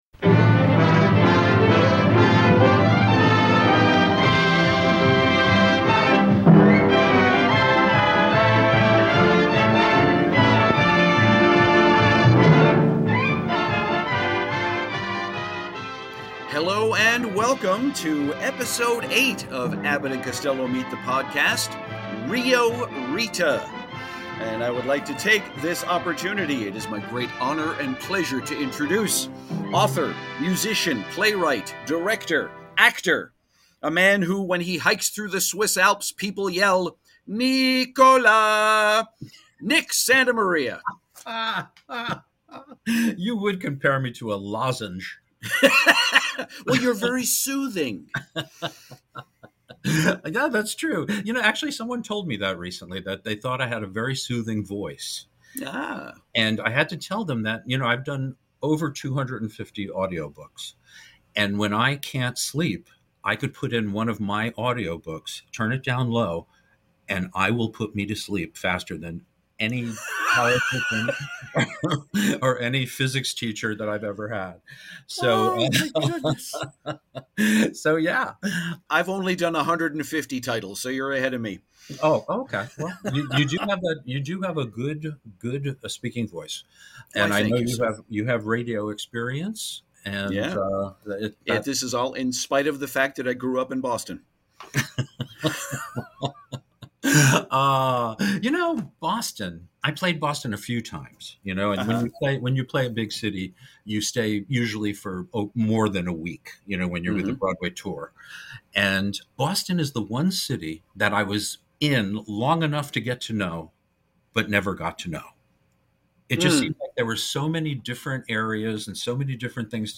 You'll hear the whole story behind this film, and it goes all the way back to Flo Ziegfeld and Wheeler and Woolsey! You'll also hear a VERY rare clip from our friends at Goodson-Todman Game Shows.